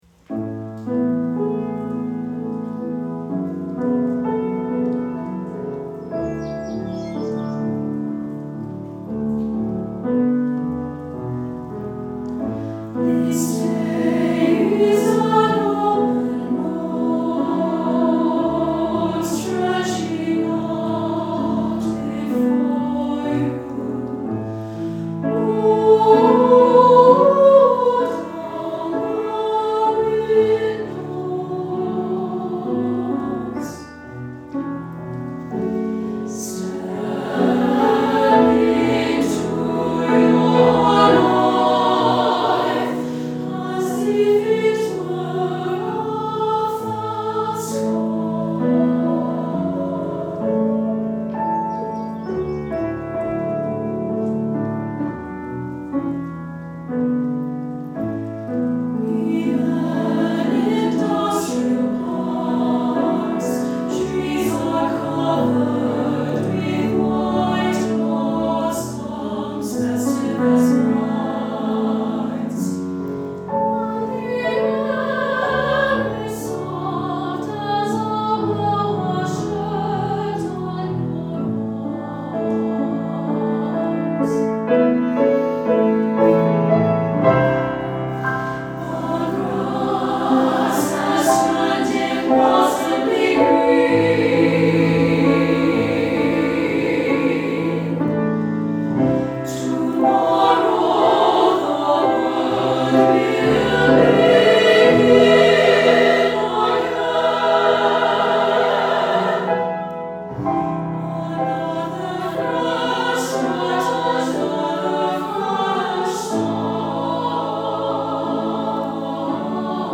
SSA choir and piano